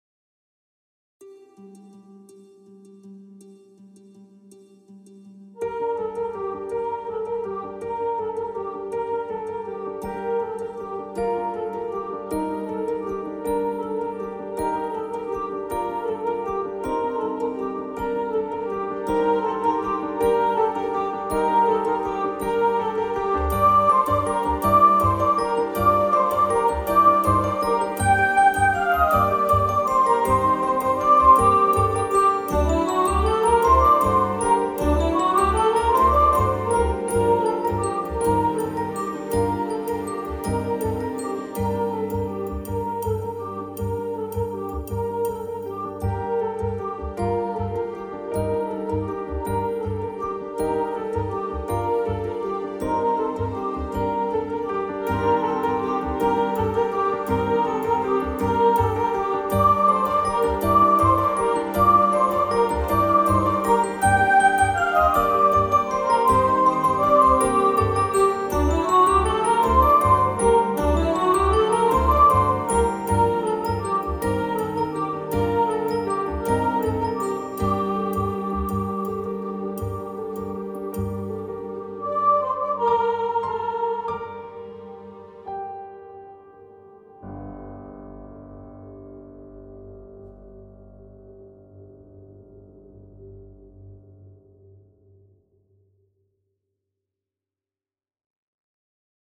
Carol-Of-The-Bells-Soprano.mp3